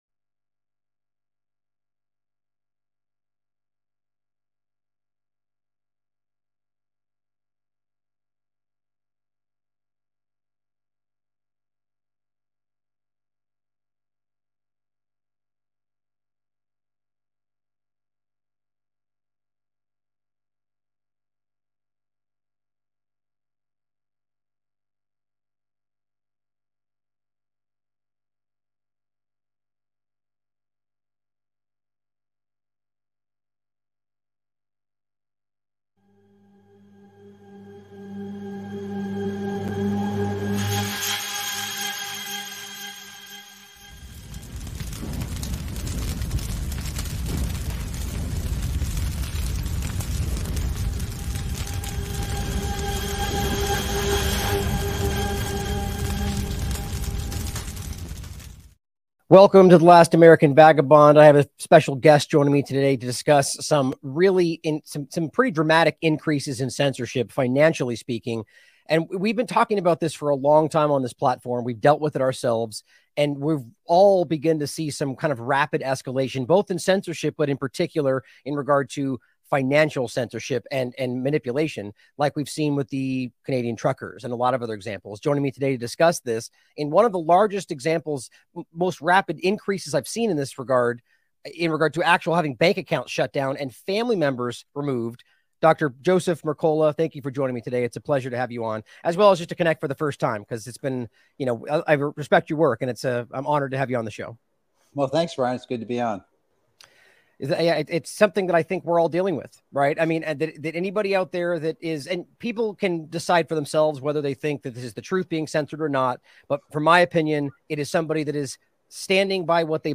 Dr. Mercola Interview - Technocratic Censorship & Financial Attacks In The Age Of The COVID Illusion